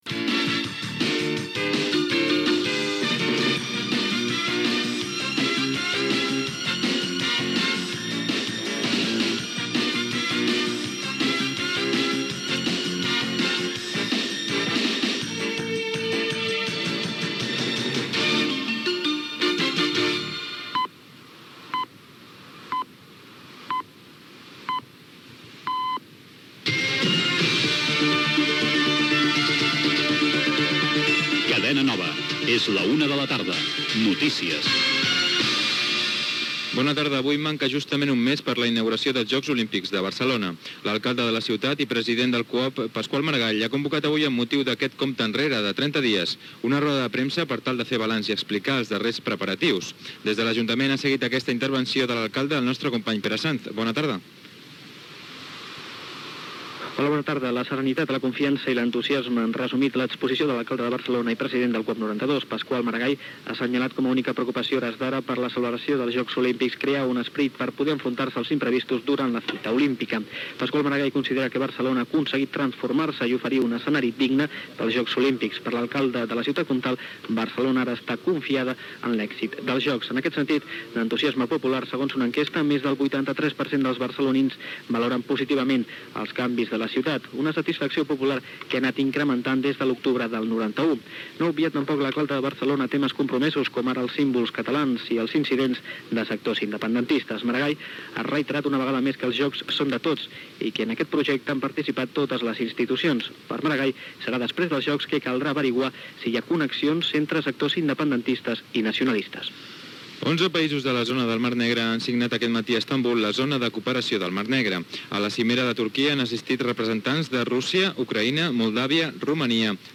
Careta del programa.
Informatiu
FM